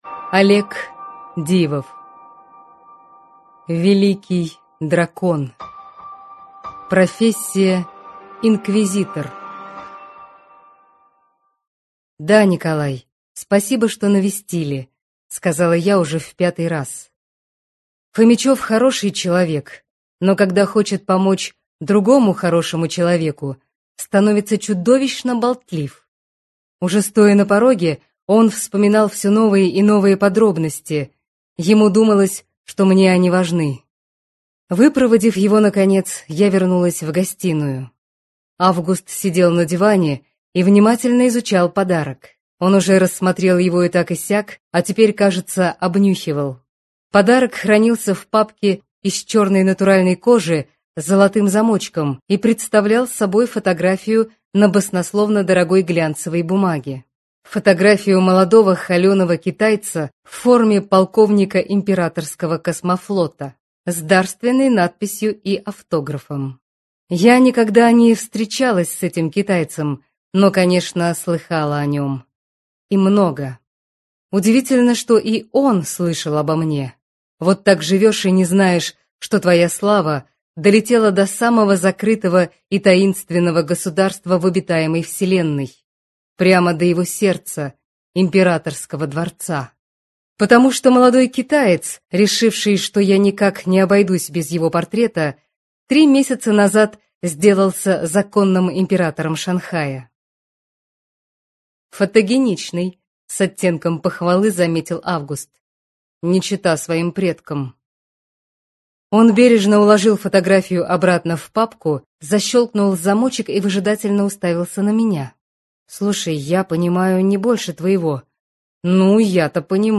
Аудиокнига Великий Дракон | Библиотека аудиокниг